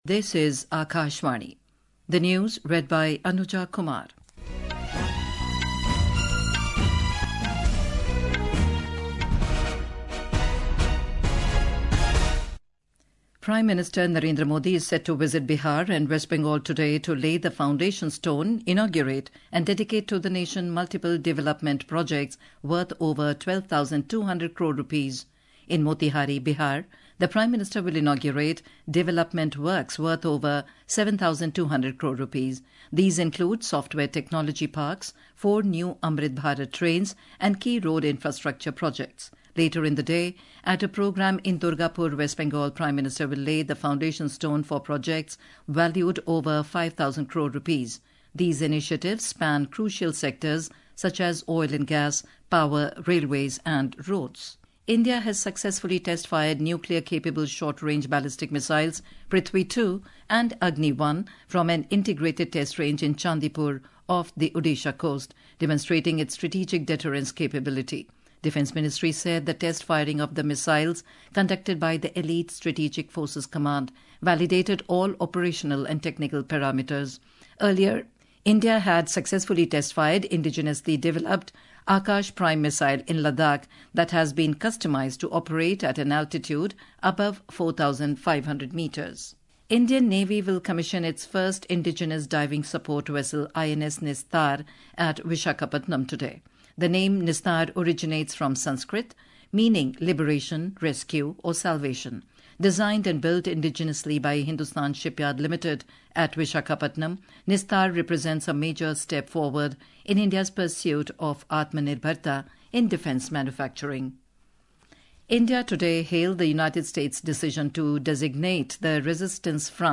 Hourly News
Hourly News | English